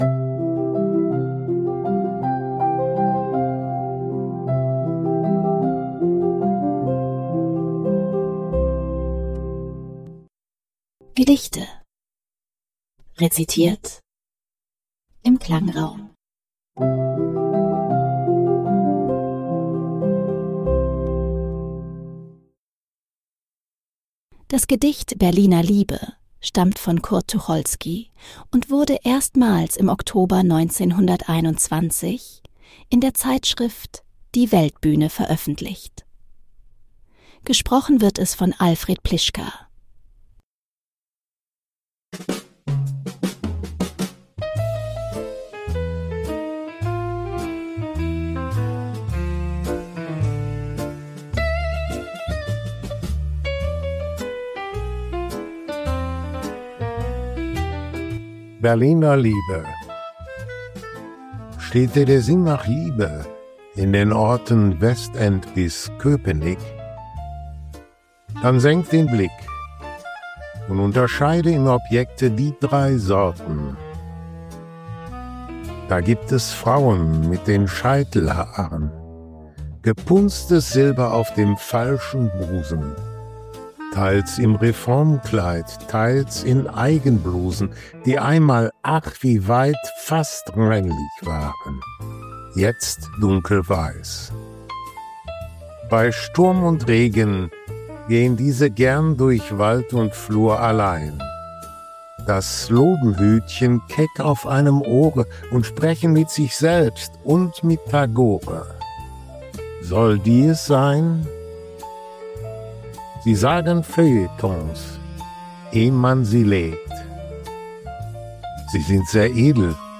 wurde mithilfe Künstlicher Intelligenz erzeugt.